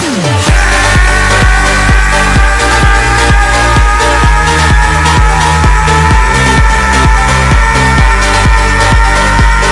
Play, download and share Power up v2 original sound button!!!!
power-up-v2.mp3